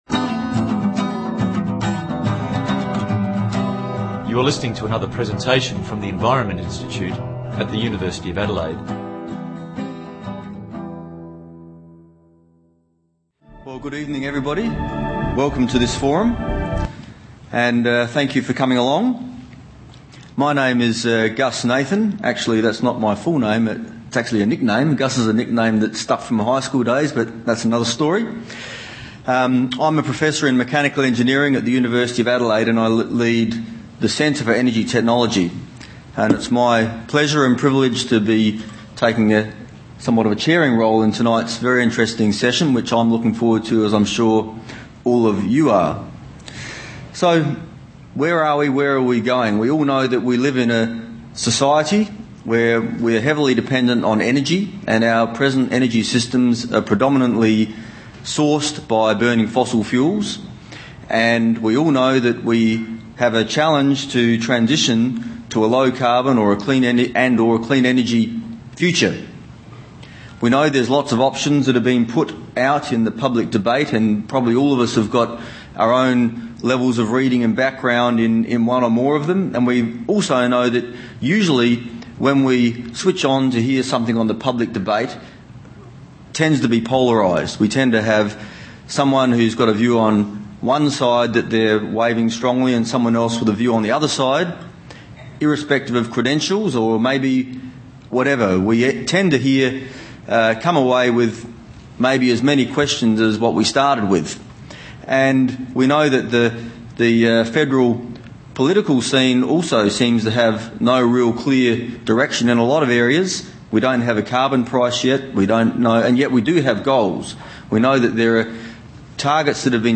This has also branched off into a live seminar series (described in detail in this post), hosted by the Royal Institution of Australia (RiAus), and has proven to be very popular (a packed house each session).